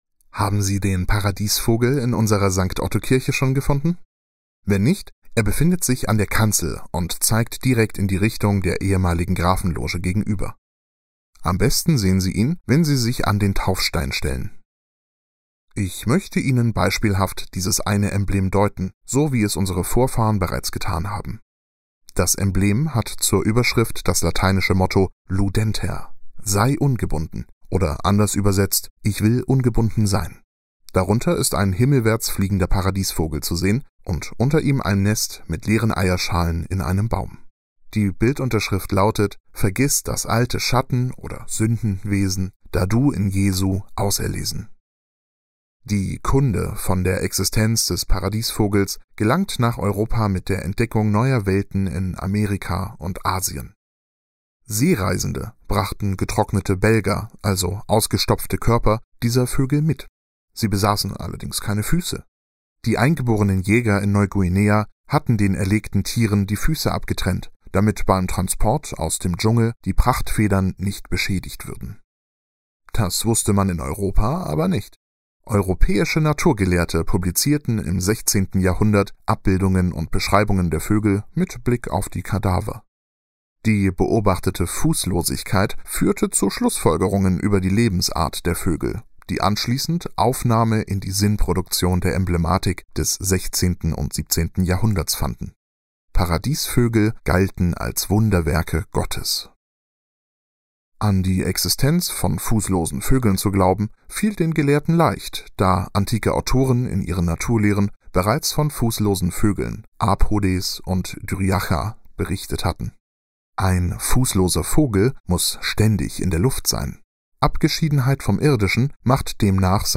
Audio Guide: